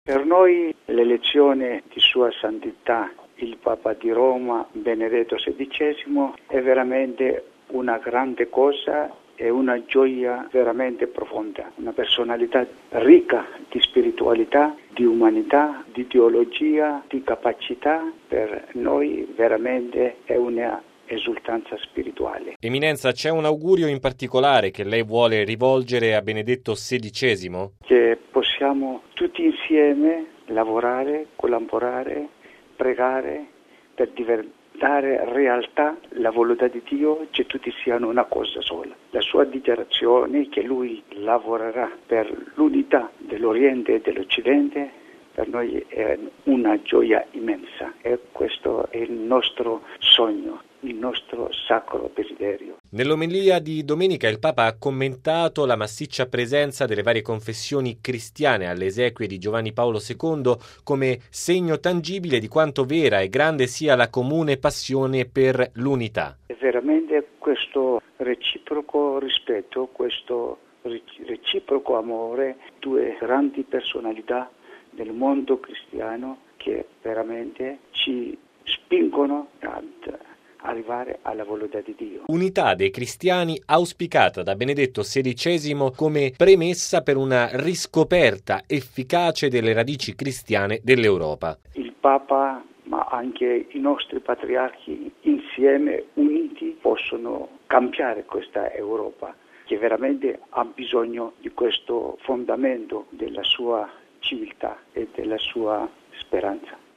All’udienza era presente tra gli altri l’arcivescovo ortodosso d’Italia del Patriarcato Ecumenico di Costantinopoli Gennadios Zervos.